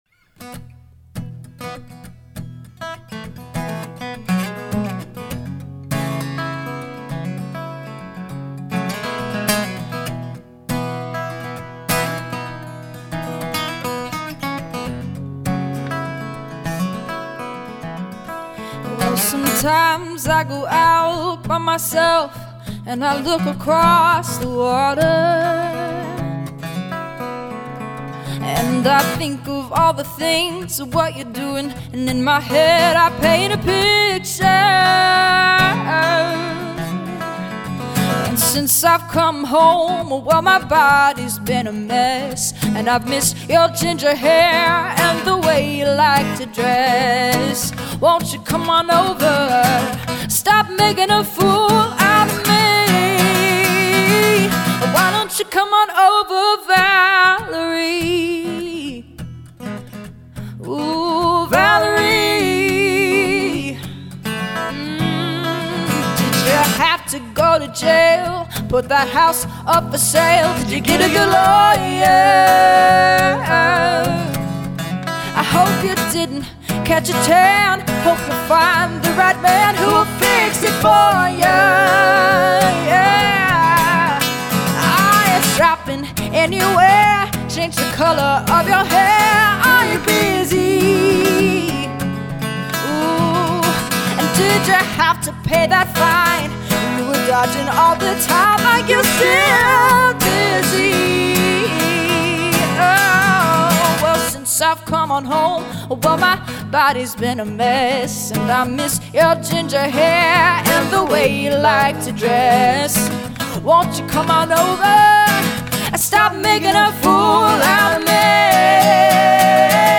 Joining forces as an acoustic duo